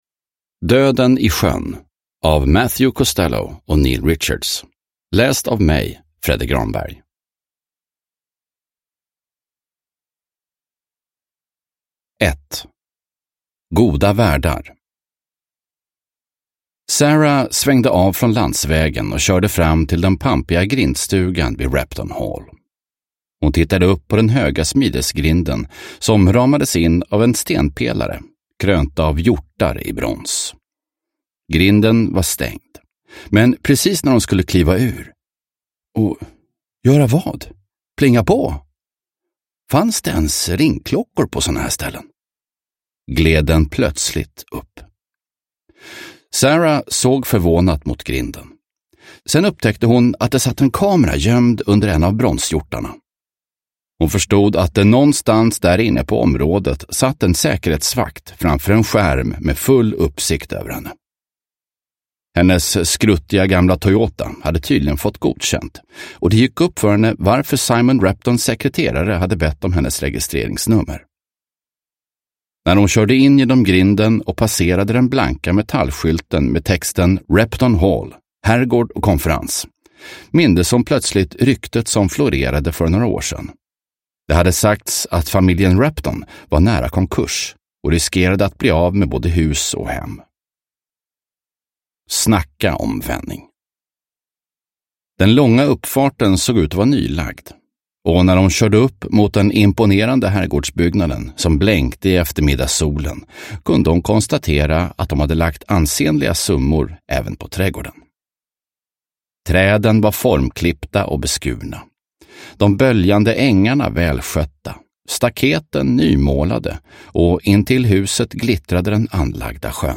Döden i sjön – Ljudbok – Laddas ner